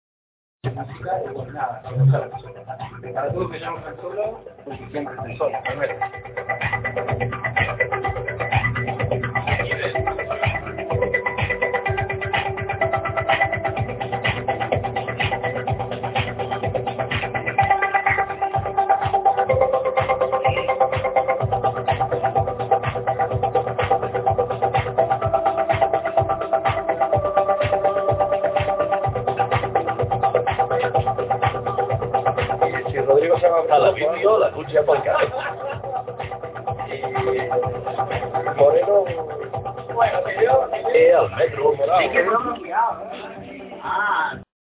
trancy minimal track